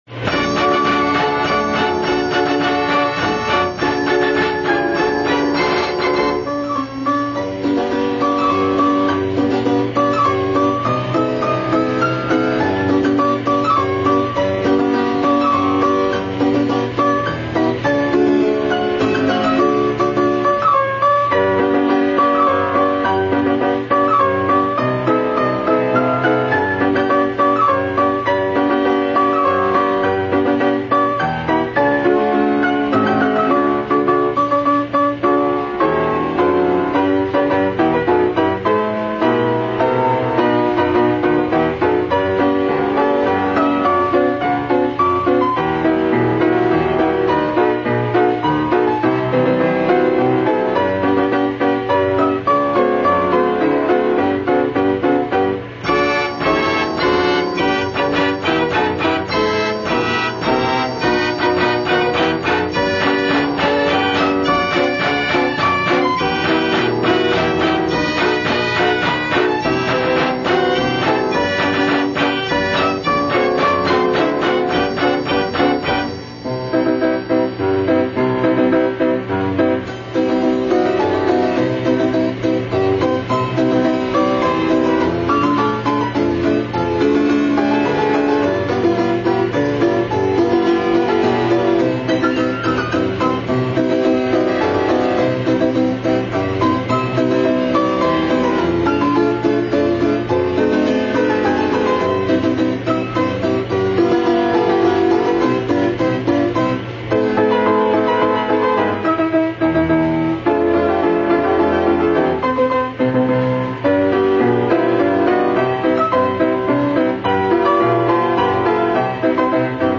Unknown Tunes on Wurlitzer APP Rolls
It is a Wurlitzer BX orchestrion with piano, mandolin, violin pipes, bass drum, snare drum, and triangle.